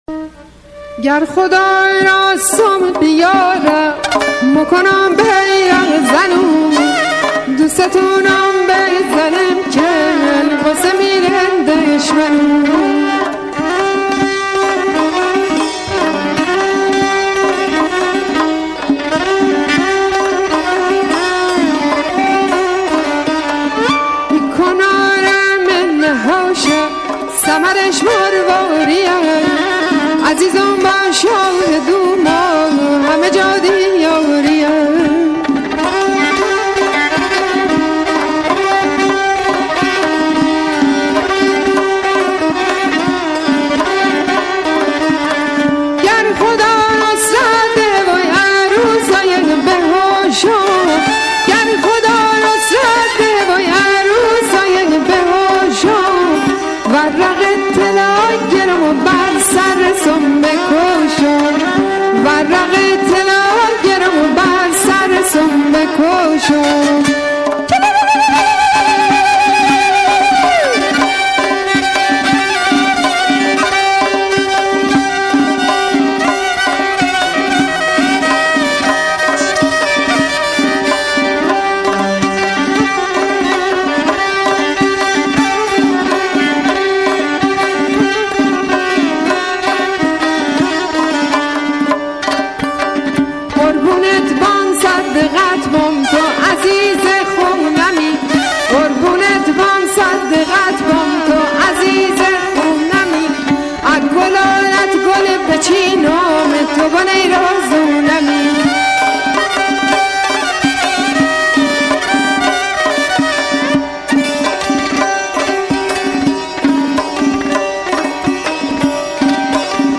ترانه قدیمی محلی شیرازی "عزیز خونه"
یکی از خوانندگان آوازهای محلی اهل ایران است.
آهنگ محلی